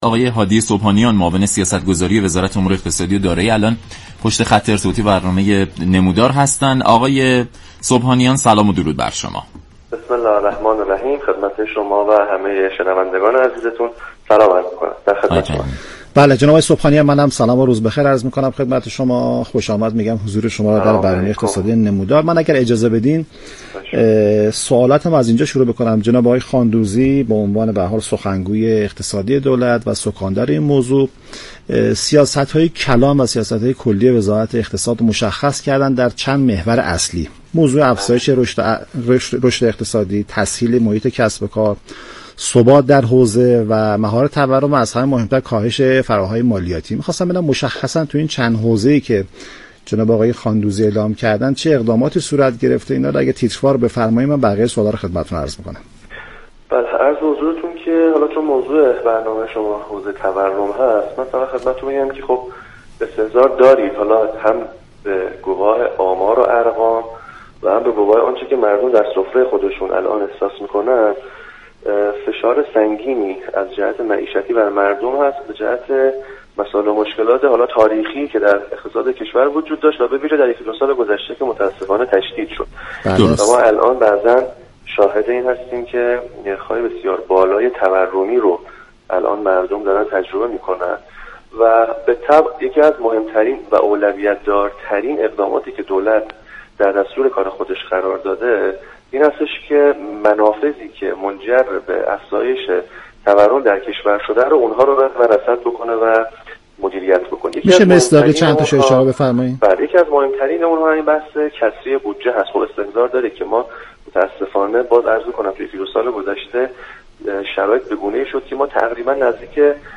معاون سیاستگذاری وزارت امور اقتصاد و دارایی گفت: اقدامات و برنامه های دولت برای مهار تورم ممكن است در میان مدت اثرگذار باشد.